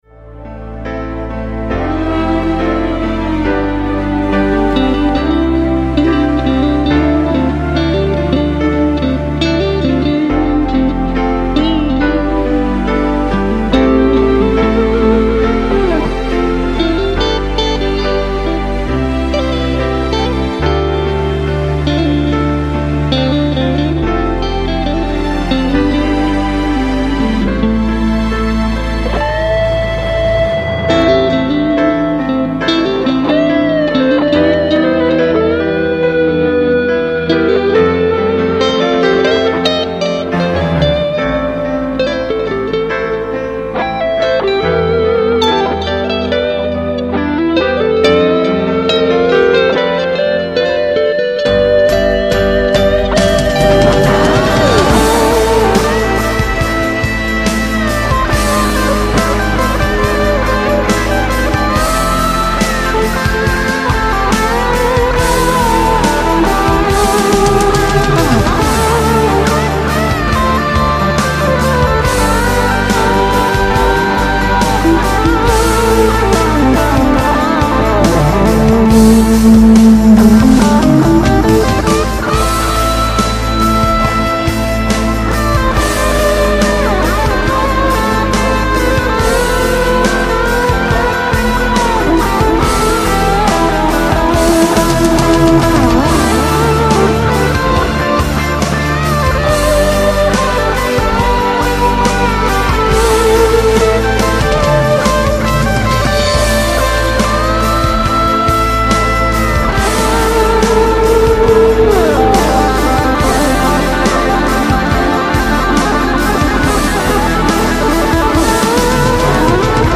PISTES AUDIO (guitares & basse) + MIDI